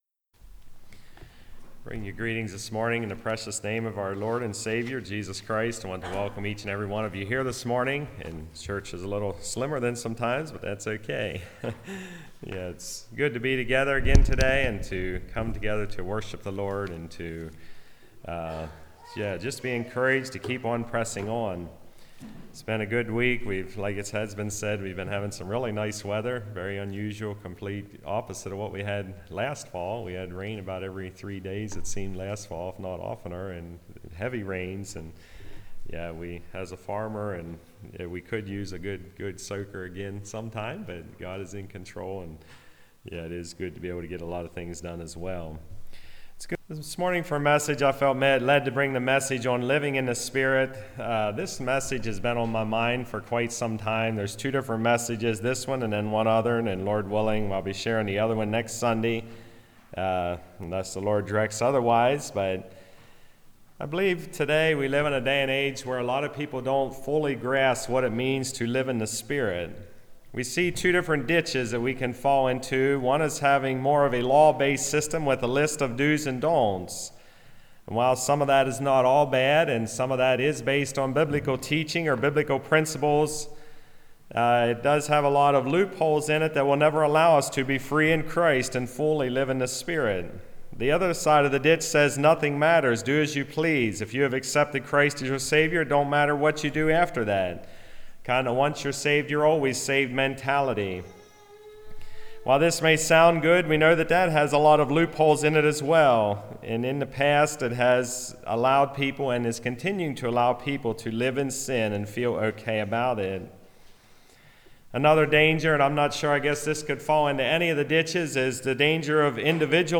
Passage: Galatians 5:16-18 Service Type: Message